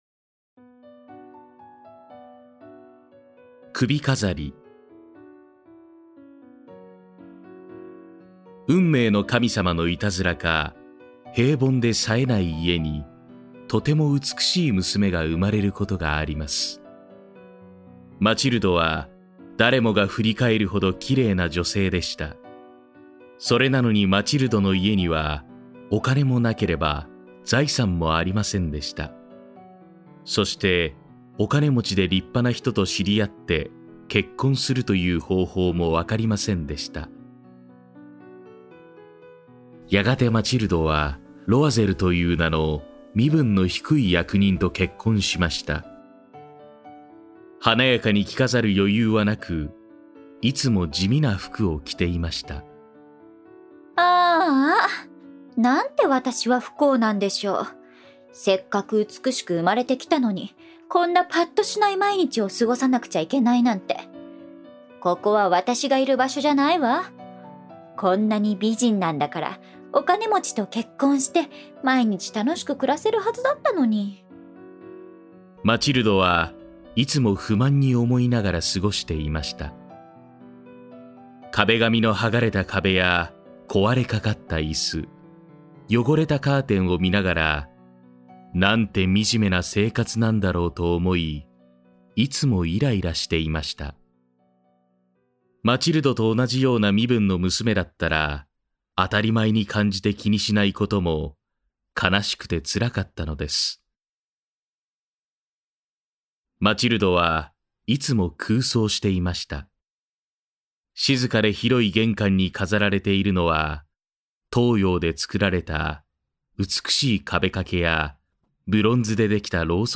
[オーディオブック] 首飾り（こどものための聴く名作 23）